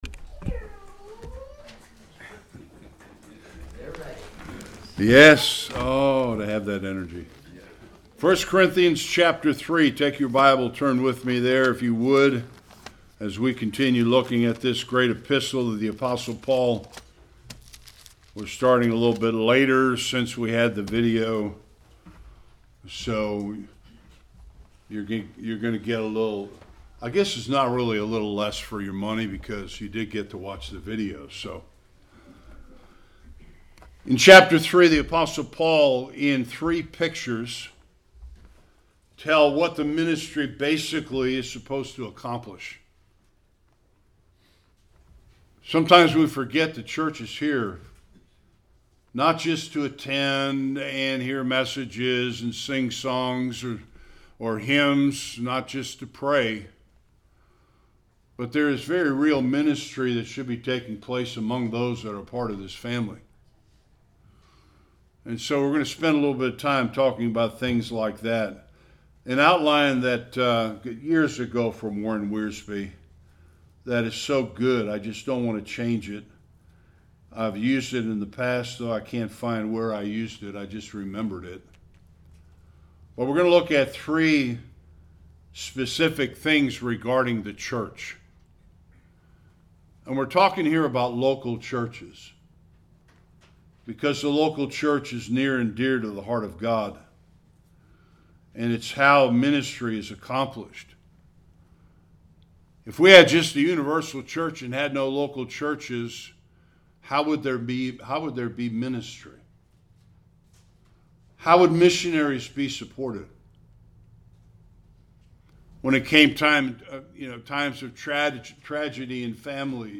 1-4 Service Type: Sunday Worship The church is much more than sitting for an hour on Sunday morning.